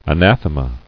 [a·nath·e·ma]